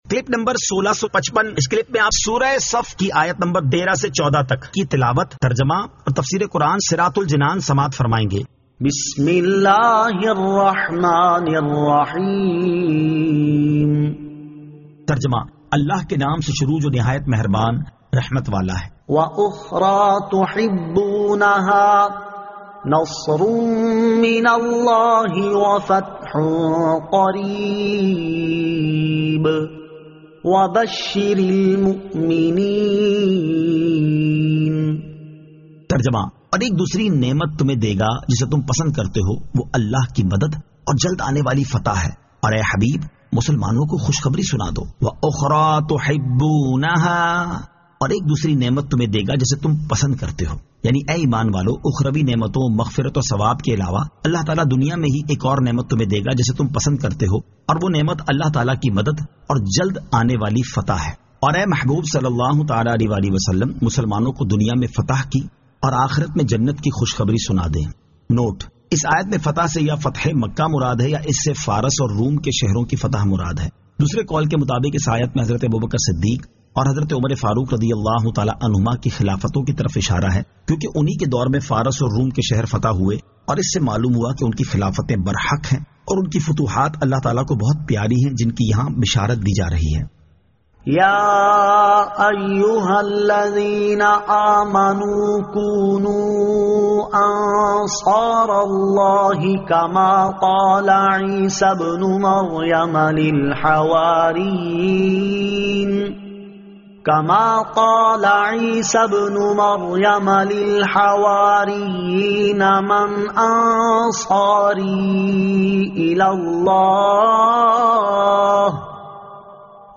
Surah As-Saf 13 To 14 Tilawat , Tarjama , Tafseer